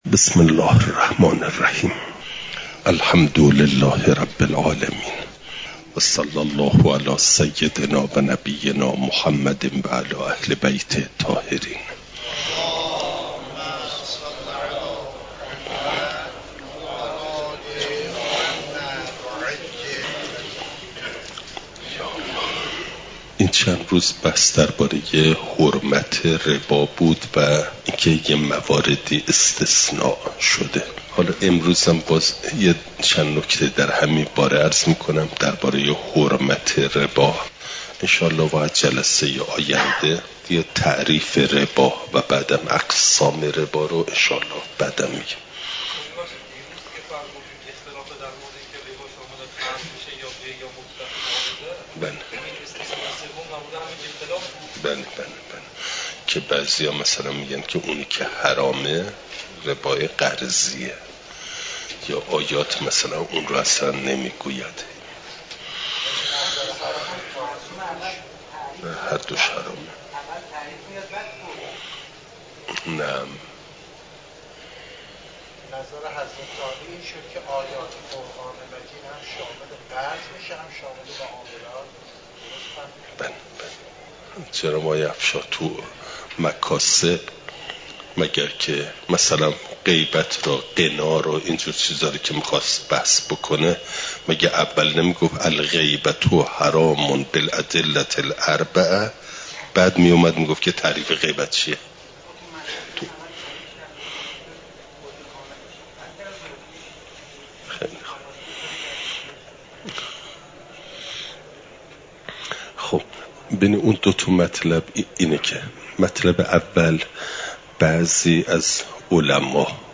نظام اقتصادی اسلام؛ مبحث ربا (جلسه۱۱) « دروس استاد